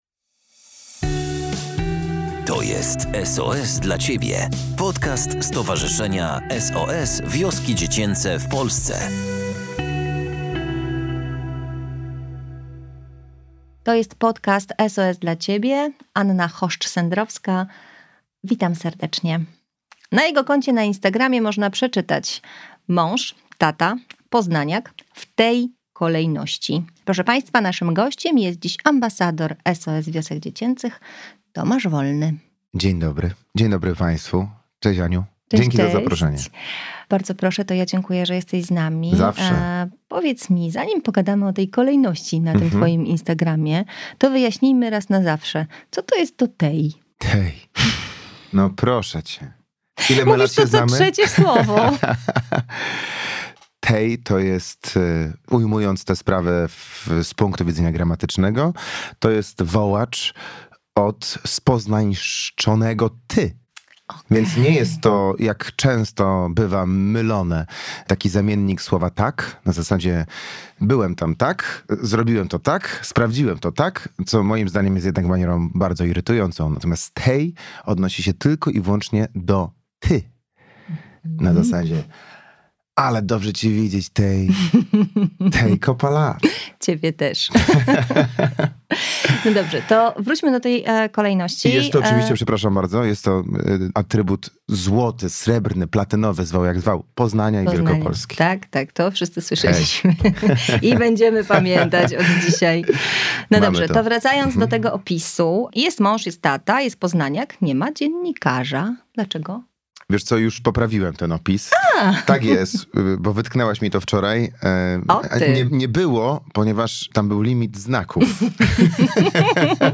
O pomaganiu i miłości – rozmowa z Tomaszem Wolnym, ambasadorem SOS